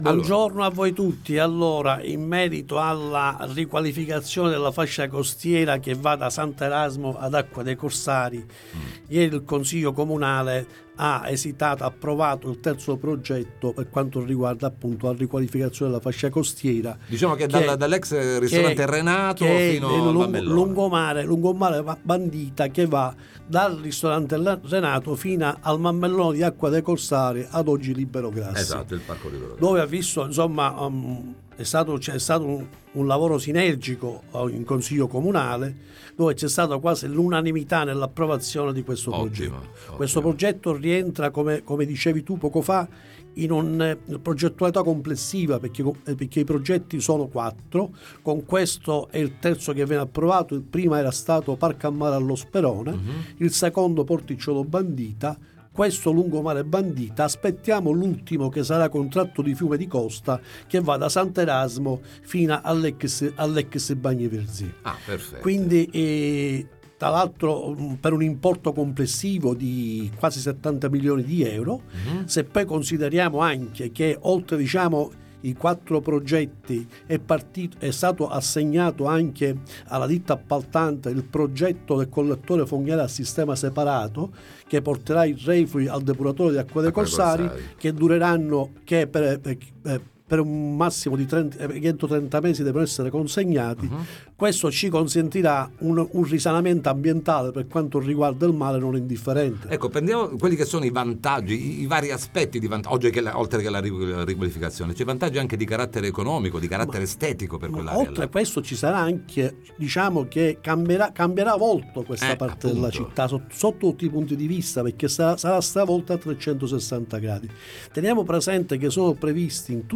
Rivalutazione della costa Sud, ne parliamo con il cons. com. Pasquale Terrani